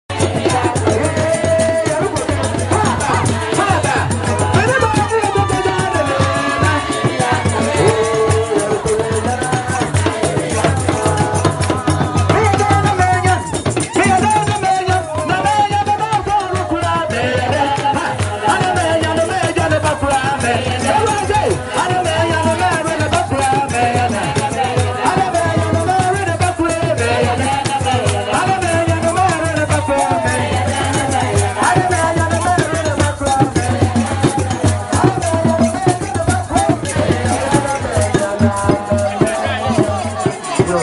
Ebibindwom